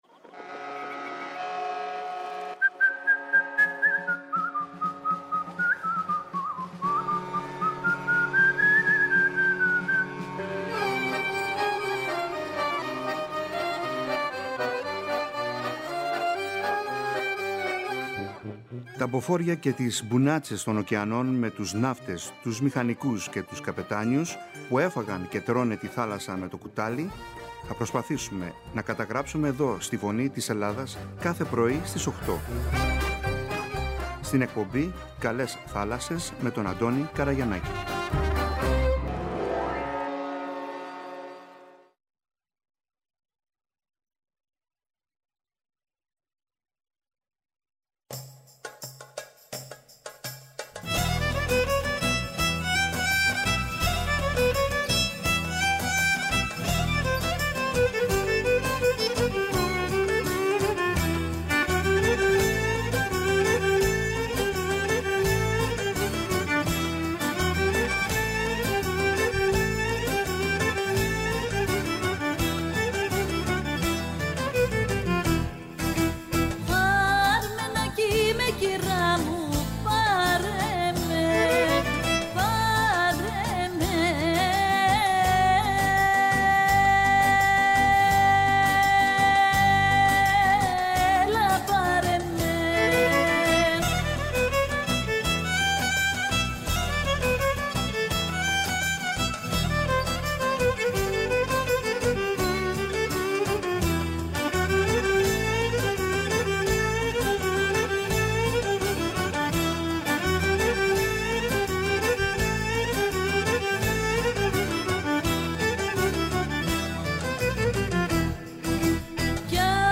Μας διηγείται με τον δικό του χαρισματικό τρόπο τρεις απίθανες ιστορίες που θυμήθηκε από τα 32 χρόνια που ταξίδεψε με πολλά καράβια στις θάλασσες και τα λιμάνια της υφηλίου.